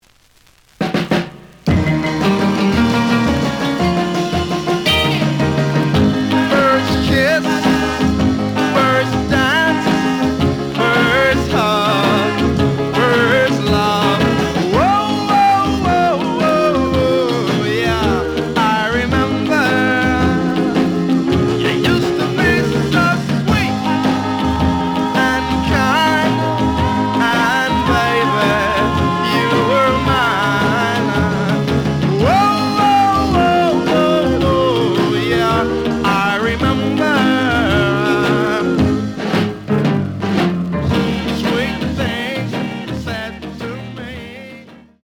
試聴は実際のレコードから録音しています。
●Genre: Rhythm And Blues / Rock 'n' Roll
●Record Grading: VG (両面のラベルにダメージ。盤に若干の歪み。プレイOK。)